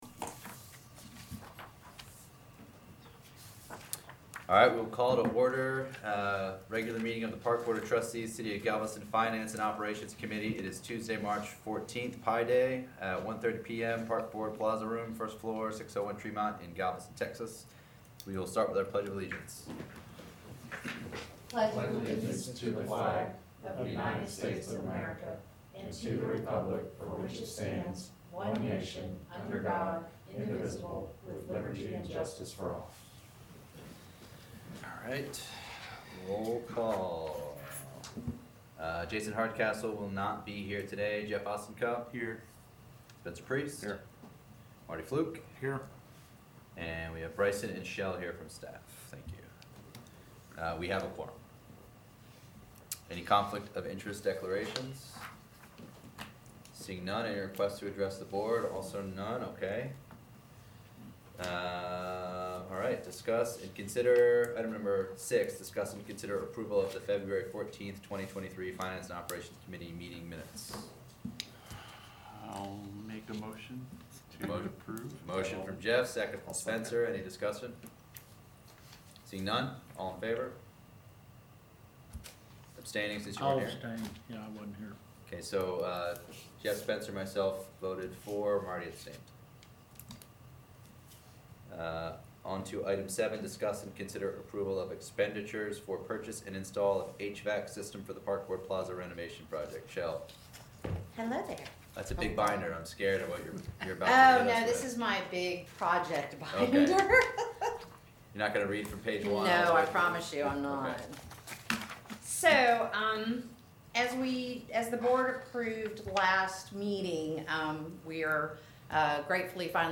Finance and Operations Committee Regular Meeting